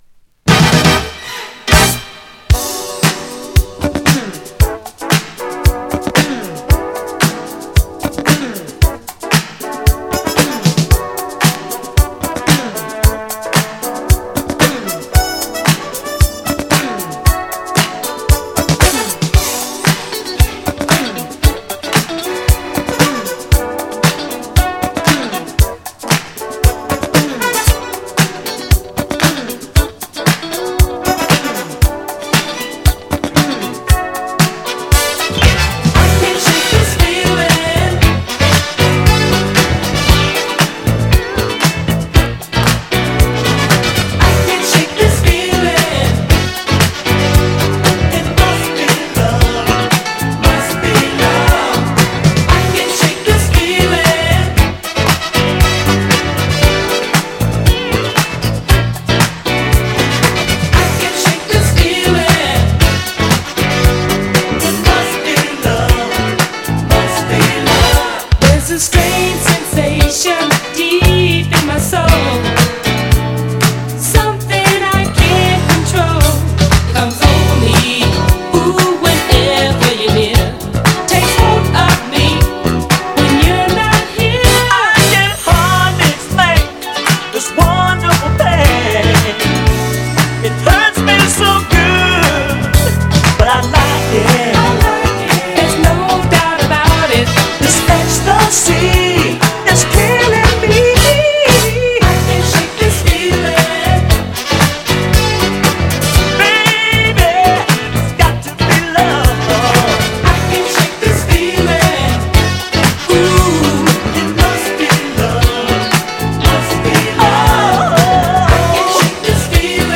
DISCO
80's FUNK〜DISCO CLASSIC !! 80'sファン…